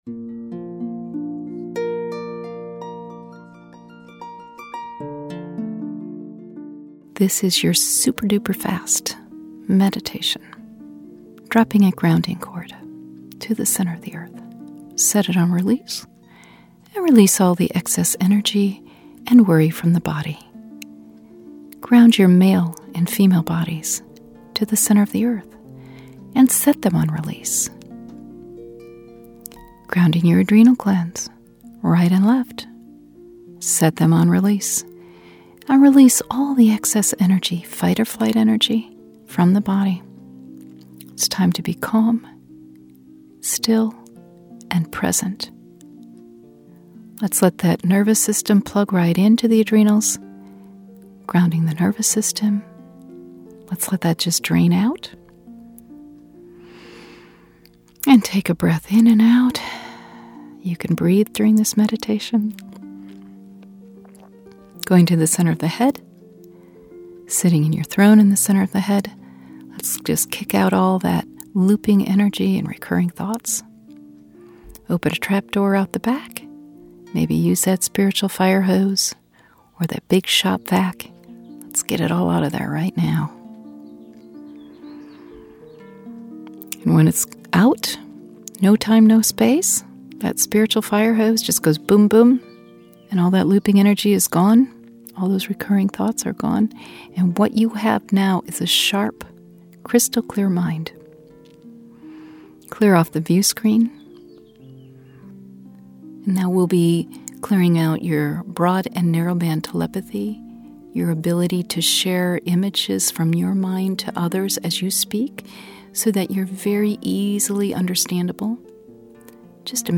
This meditation includes key clear outs and the ‘quick version’ of the restoring processes that are a part of my longer meditation.